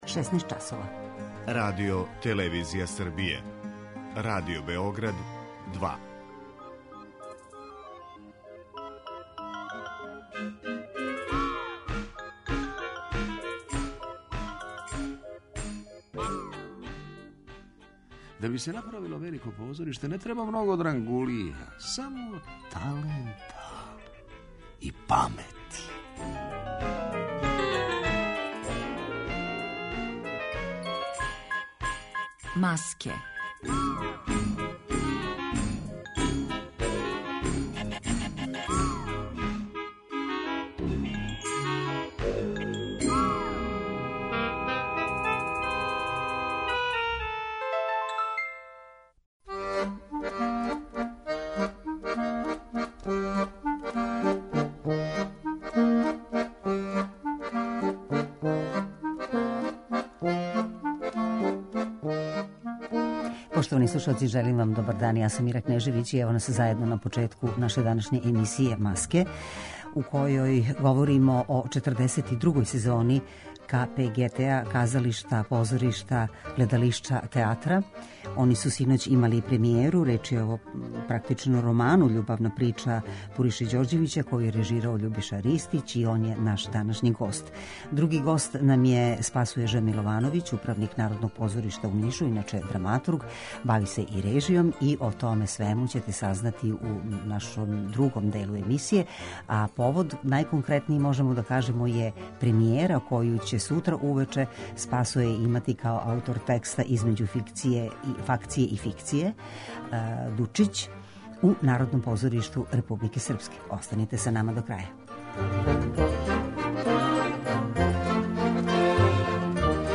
Емисија о позоришту
Гост емисије је Љубиша Ристић, а разговараћемо о предстојећим представама у овом позоришту до краја сезоне.